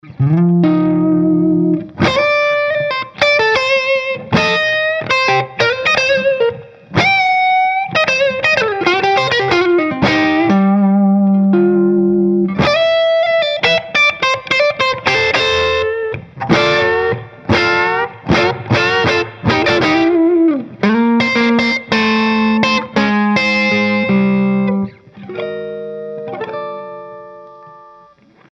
Inflames é um falante com voice britânico inspirado em um dos mais renomados timbres dessa linhagem, apresenta harmônicos extremamente detalhados e complexos, graves encorpados e definidos, alcance médio rico e detalhado com características do timbre usado por Slash, Steve Stevens e Peter Frampton.
BLUES
Inflames_blues.mp3